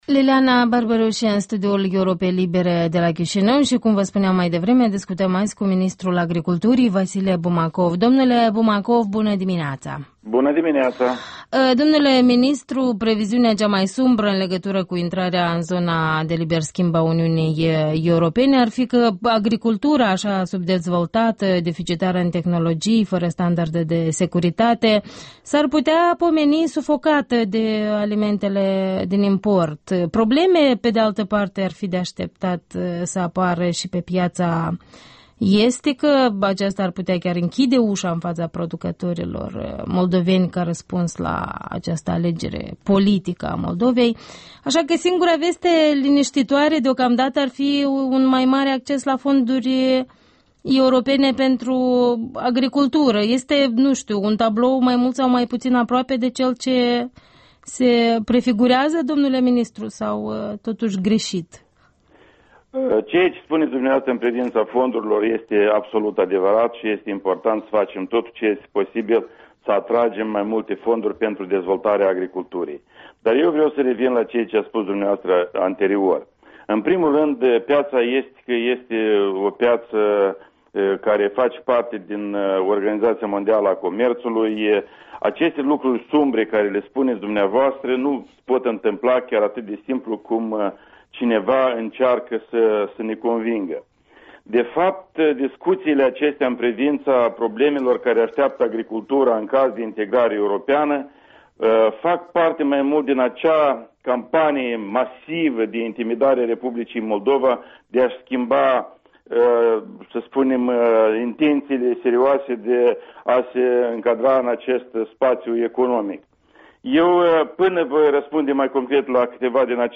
Interviul matinal la Europa Liberă: cu Vasile Bumacov